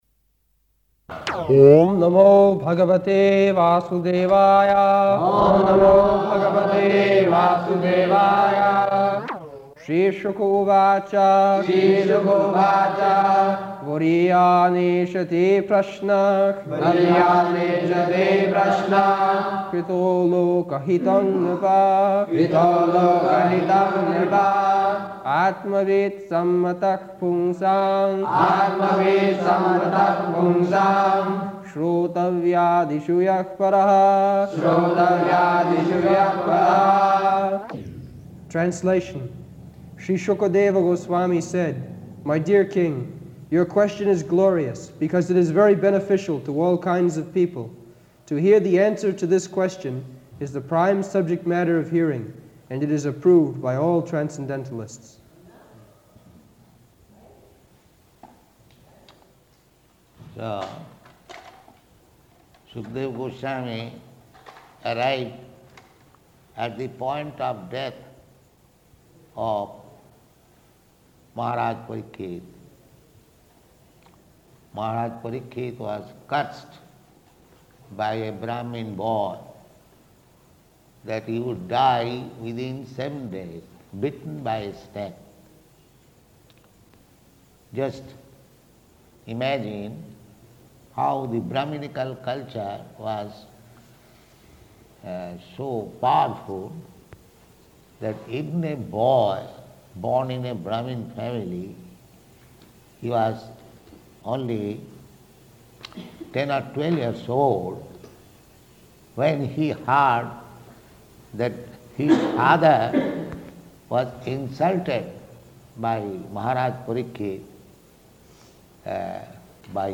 November 4th 1973 Location: Delhi Audio file
[leads chanting of verse, etc.]